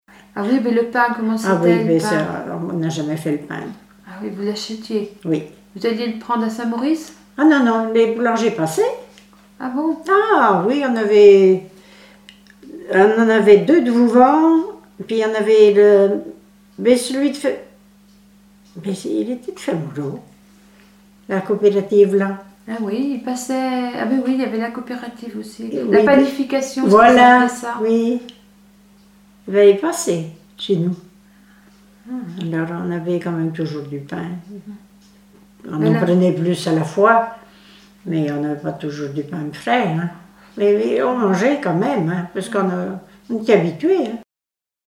Témoignages sur la vie à la ferme